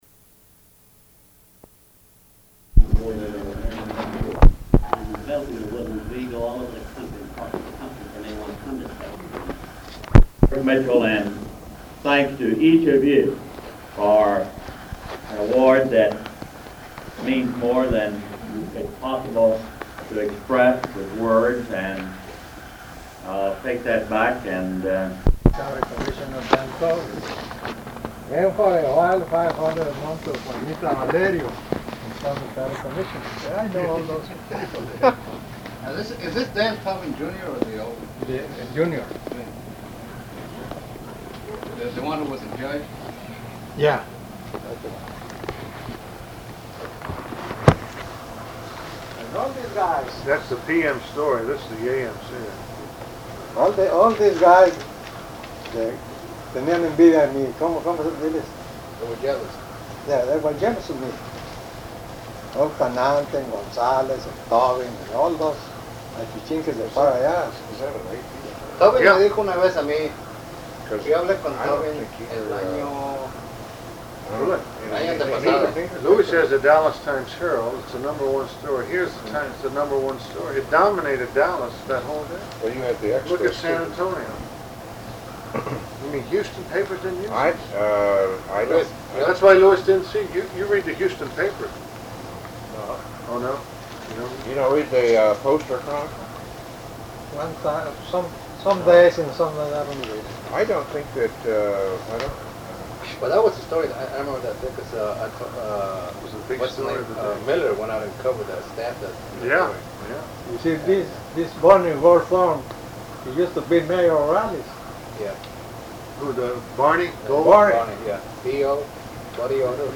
Format Audio tape
Specific Item Type Interview Subject Congressional Elections Texas